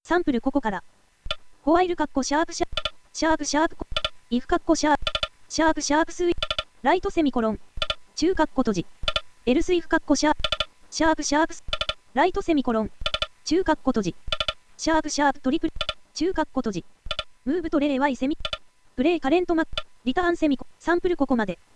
この時の「タブやスペース」がいくつあるのかを音で知らせます。
読み上げサンプル